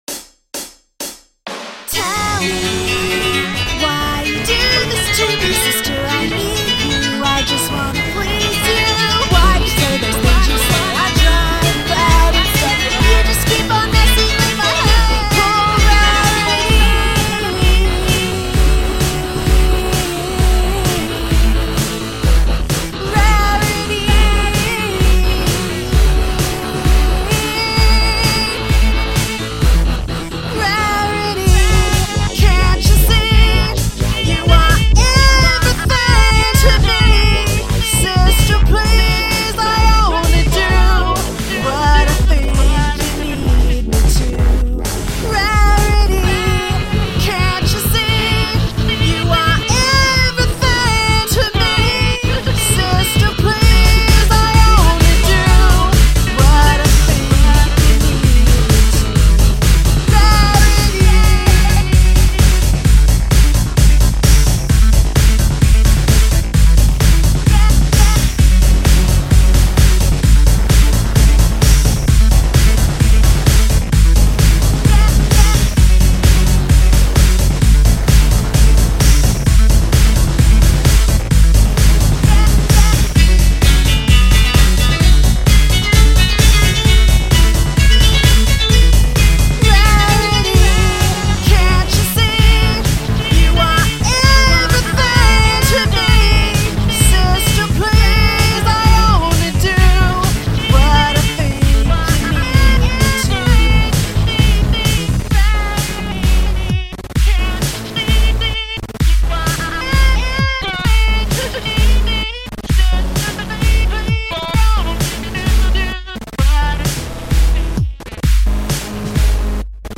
3 Also, I think I fixed my volume problem.